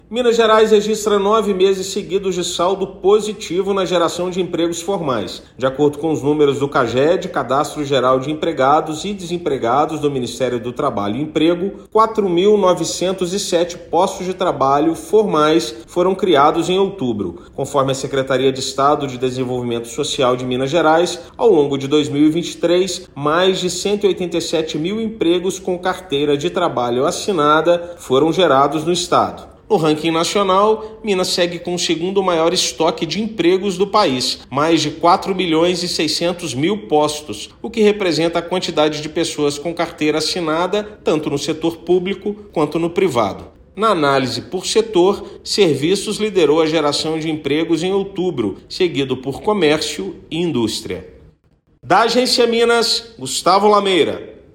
Estado acumula mais de 187.000 postos de trabalho formais criados ao longo de 2023. Ouça matéria de rádio.